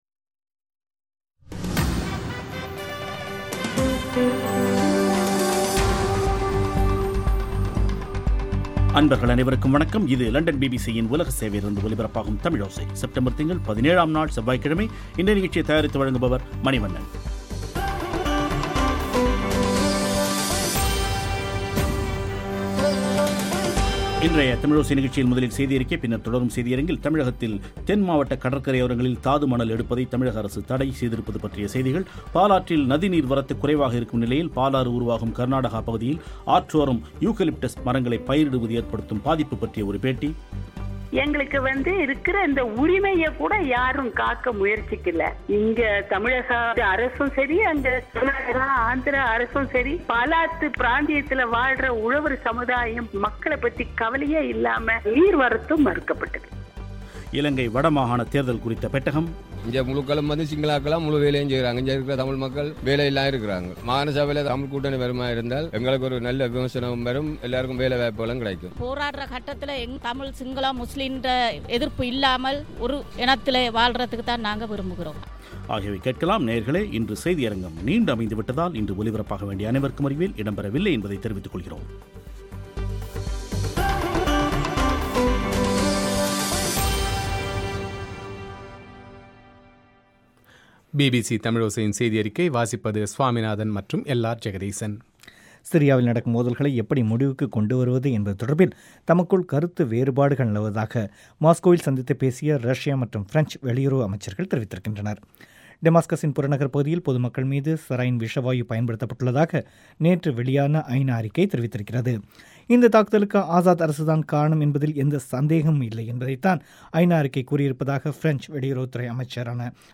இன்றைய தமிழோசை நிகழ்ச்சியில் தமிழகத்தில் தென் மாவட்டக் கடற்கரையோரங்களில் தாது மணல் எடுப்பதை தமிழக அரசு தடை செய்திருப்பது பற்றிய ஒரு பேட்டி பாலாற்றில் நதி நீர் வரத்து குறைவாக இருக்கும் நிலையில், பாலாறு உருவாகும் கர்நாடகா பகுதியில் ஆற்றோரம் யூகலிப்டஸ் மரங்களைப் பயிரிடுவது ஏற்படுத்தும் பாதிப்பு குறித்த ஒரு பேட்டி சீதா மங் ஆவே என்ற சிங்கள திரைப்பட்த்தில் ராவணனை அவமதிப்பதாக்க் காட்சிகள் வருவதால் அதைத் தடை செய்யவேண்டும் என்று ஒரு புத்த பிக்குகள் அமைப்பு கோரியிருப்பது பற்றிய குறிப்பு